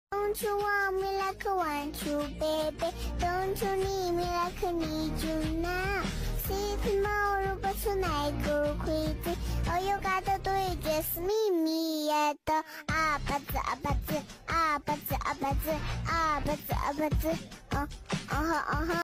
So ai but cute
The voice actually suits the cat. 😂